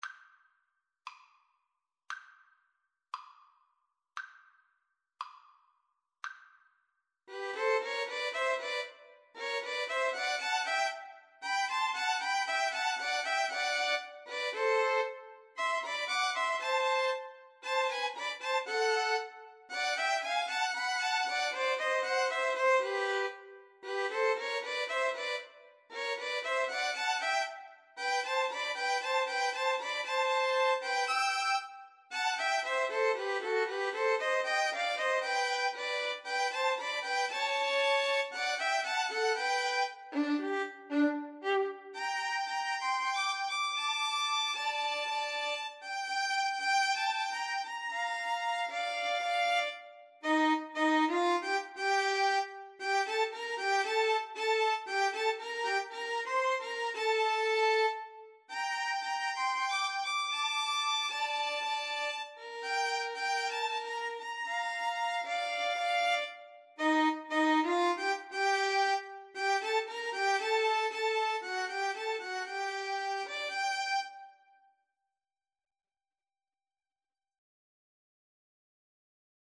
Violin 1Violin 2Violin 3
2/4 (View more 2/4 Music)
Violin Trio  (View more Intermediate Violin Trio Music)
World (View more World Violin Trio Music)
Argentinian